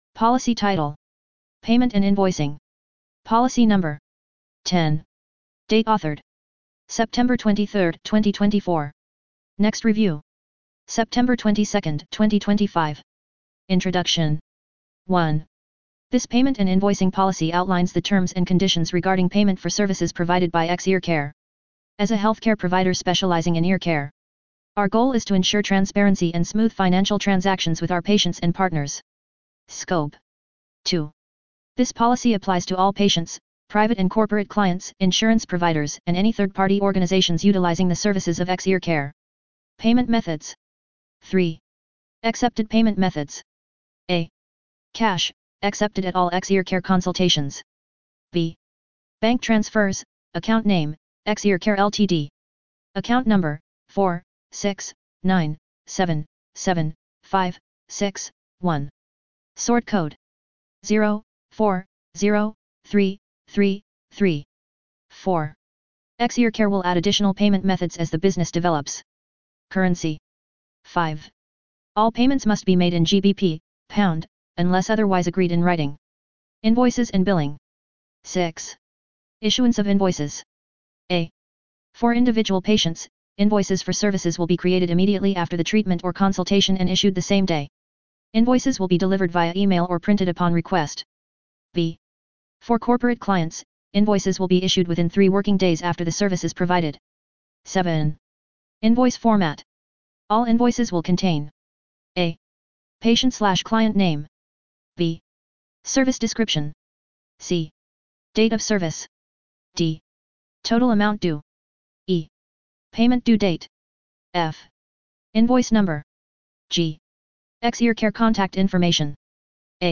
Narration of Payments and Invoicing Policy